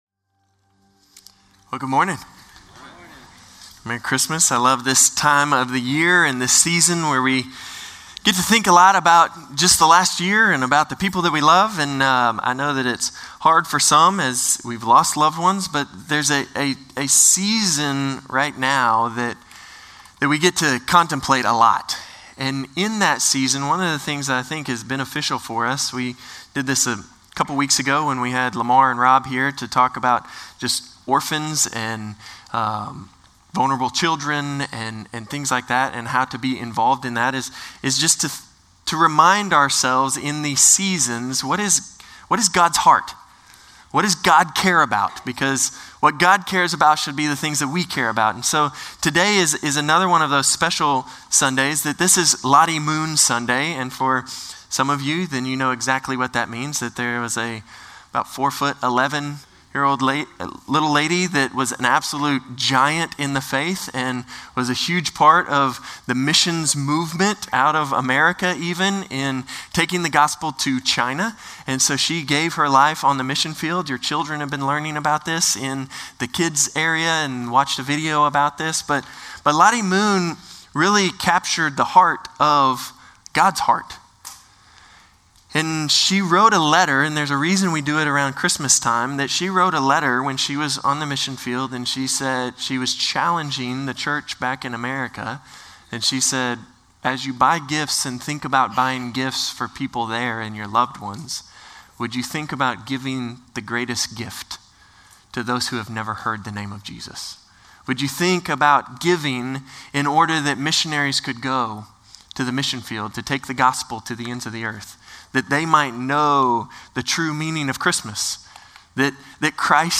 Norris Ferry Sermons Dec. 8, 2024 -- Lottie Moon -- Psalm 67 Dec 08 2024 | 00:34:01 1x 00:00 / 00:34:01 Subscribe Share Spotify RSS Feed Share Link Embed